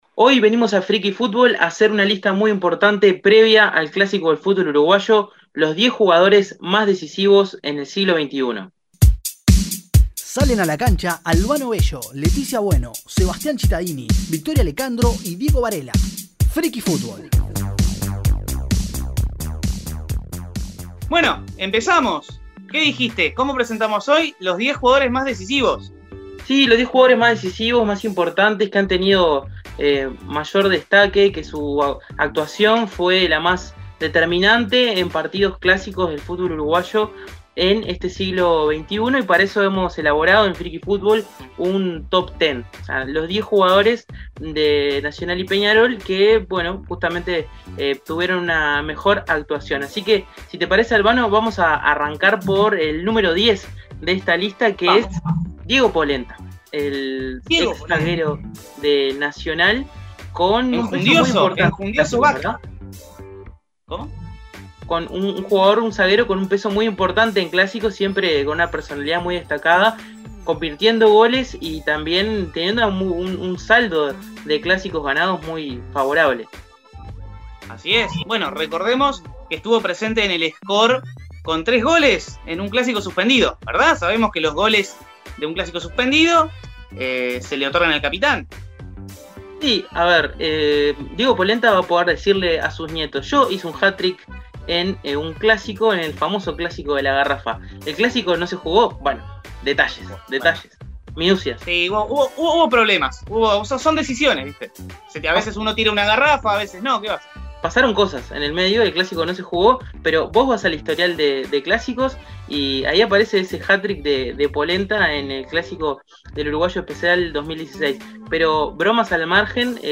charla